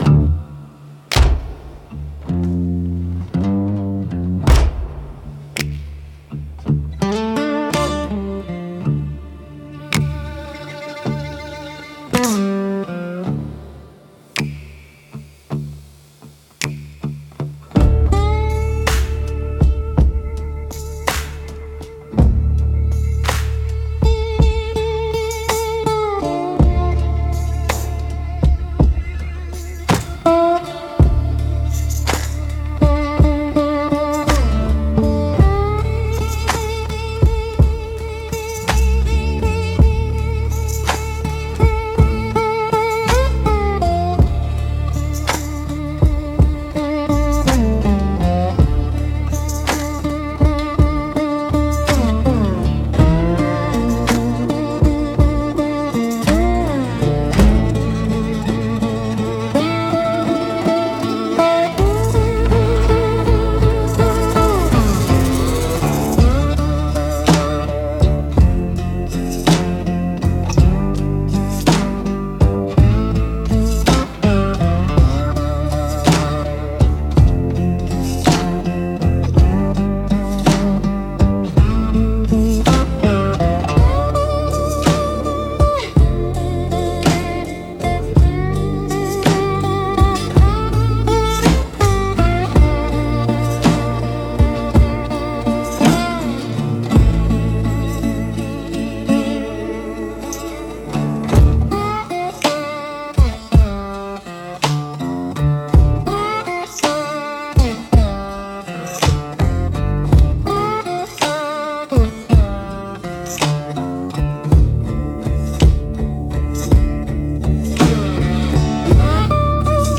Instrumental - The Company Store Blues 3.23